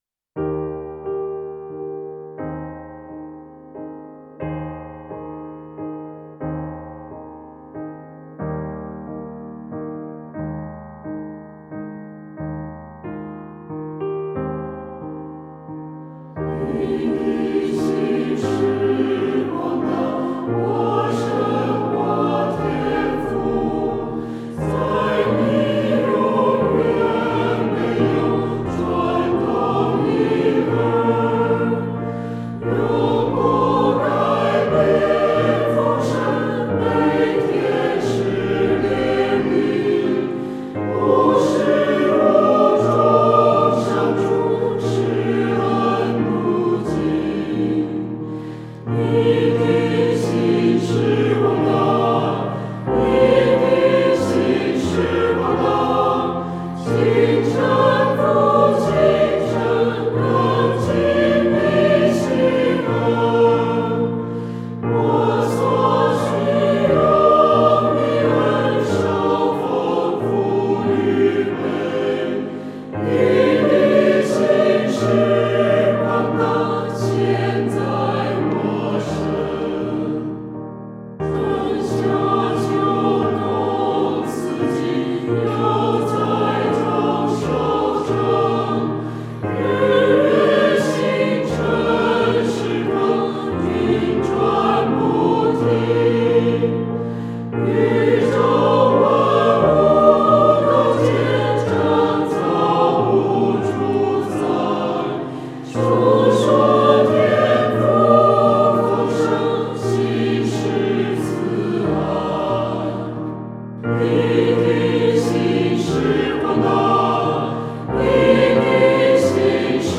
唱诗：你的信实广大（新83）